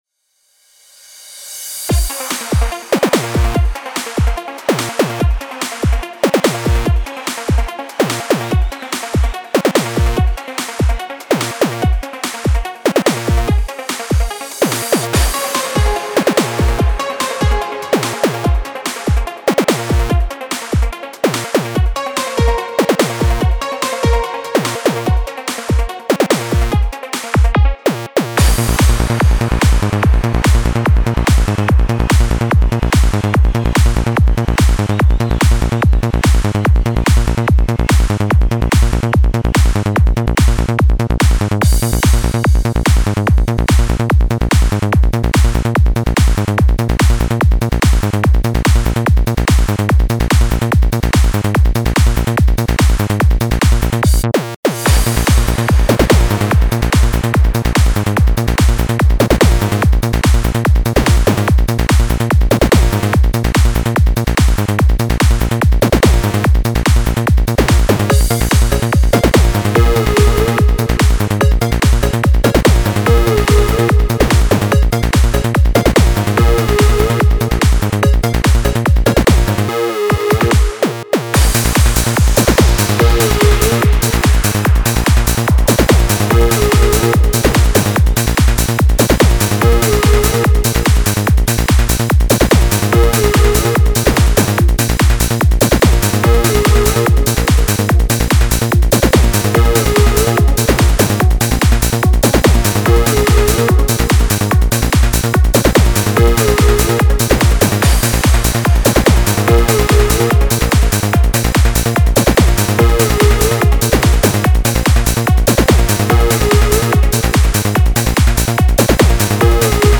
Файл в обменнике2 Myзыкa->Psy-trance, Full-on
Style: Full On